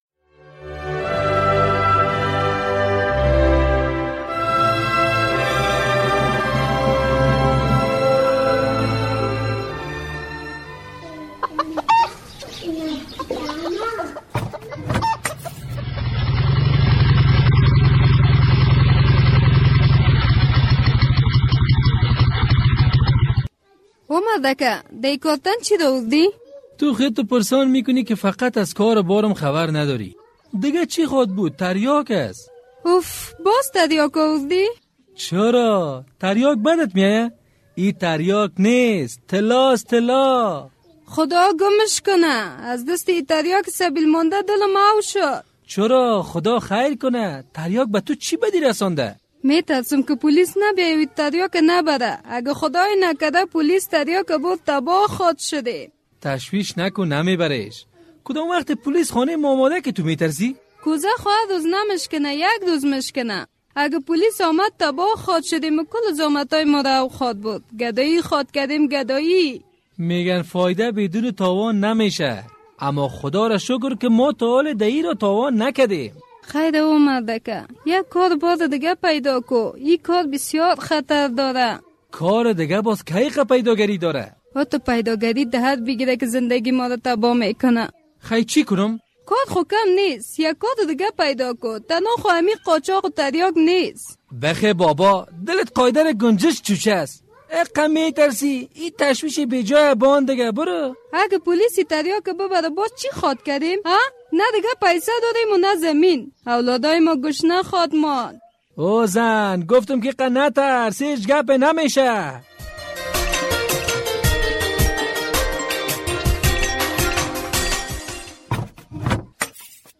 درامه کاروان زهر